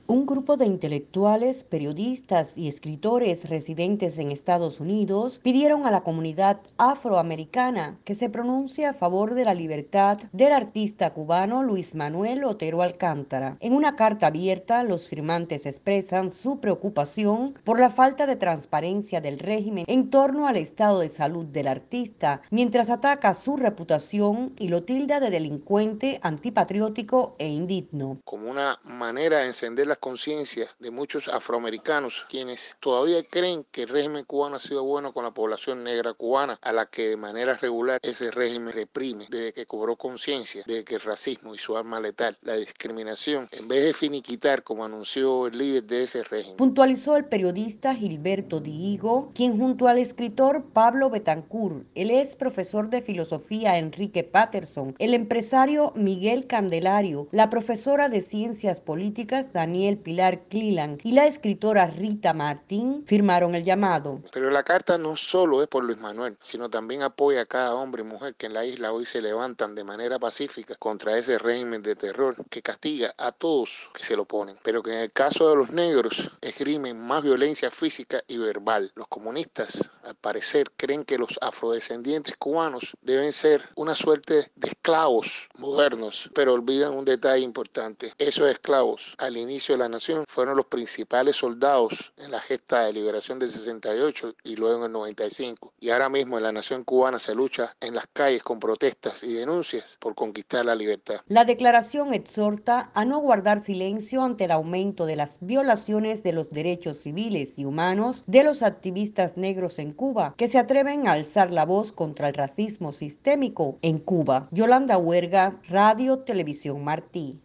Firmante de la petición de solidaridad con LMOA habla para Radio Martí